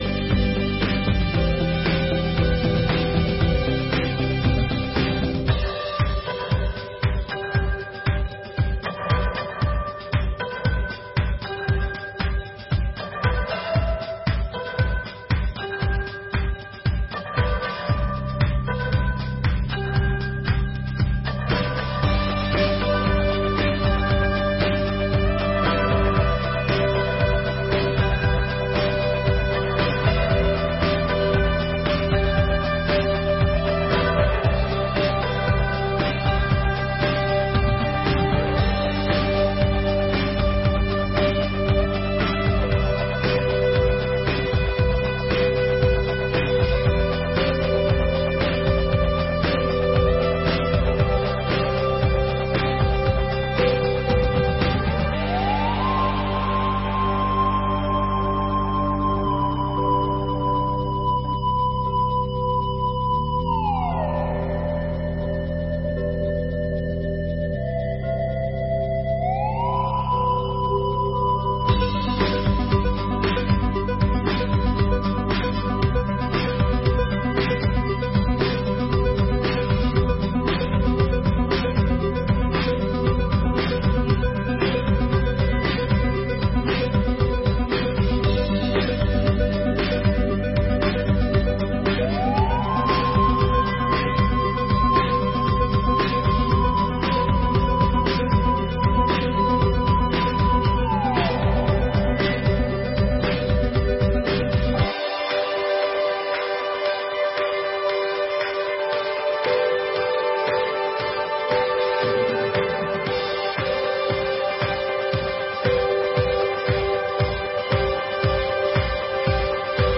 5ª Sessão Ordinária de 2023